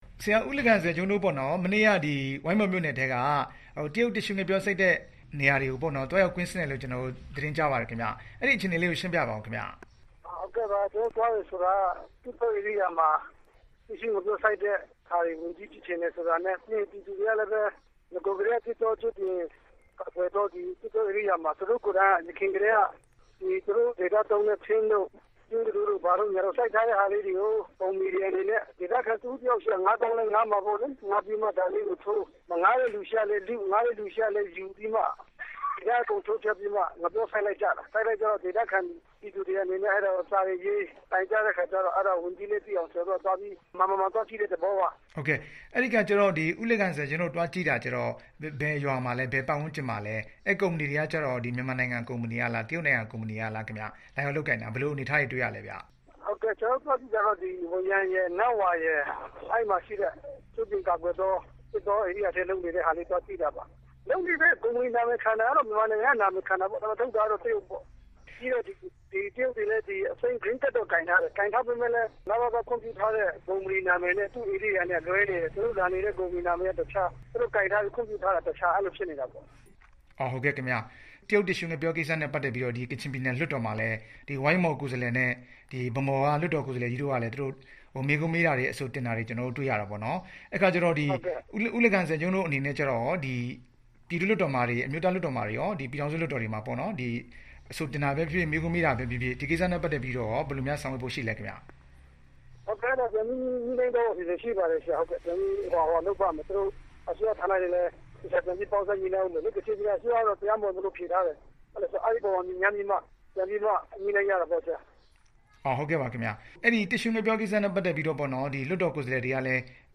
တရားမဝင် တစ်ရှူးငှက်ပျော စိုက်နေတဲ့အကြောင်း မေးမြန်းချက်